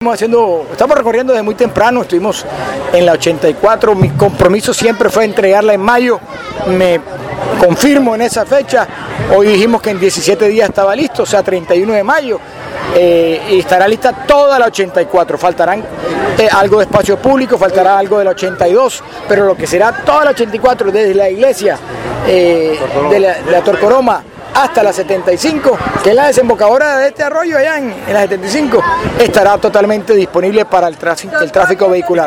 El Alcalde se pronunció en medio del acto en el que se entregó la Plaza del Pescador en el barrio Siape.